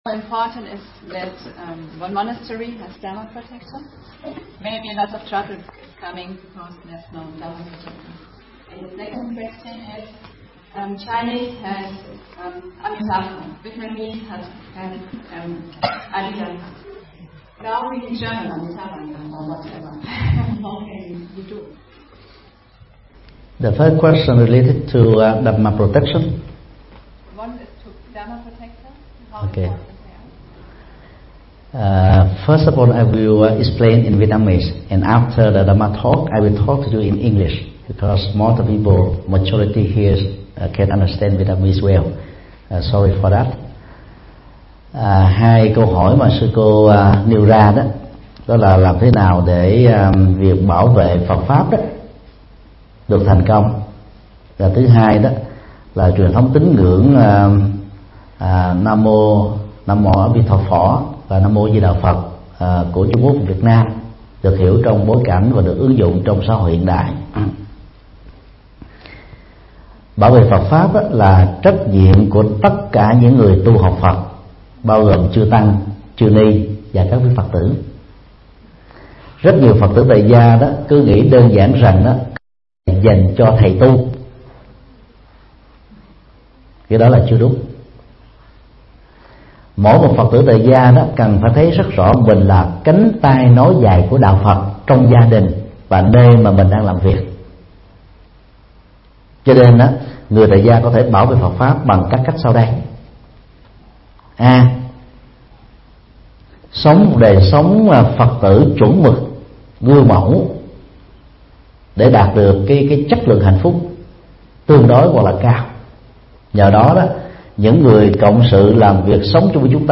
Vấn đáp: Hướng dẫn bảo vệ Phật pháp, niệm Phật – Thầy Thích Nhật Từ